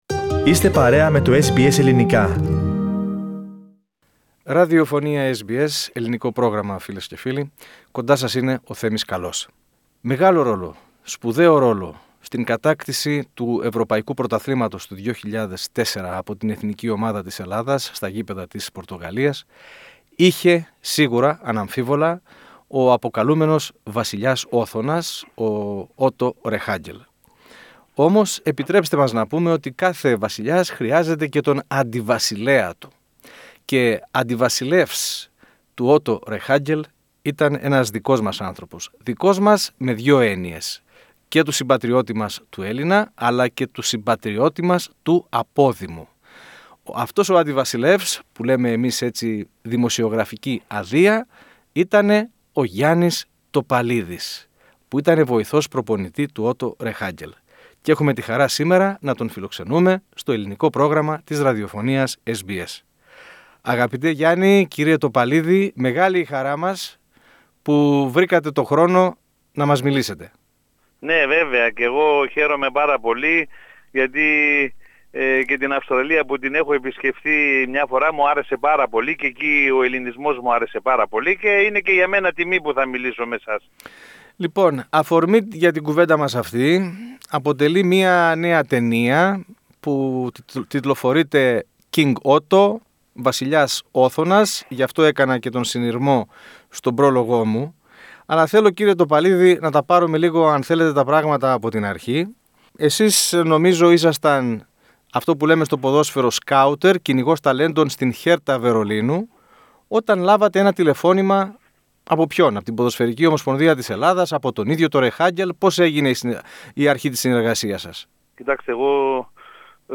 Η συνομιλία μας έχει ως ακολούθως: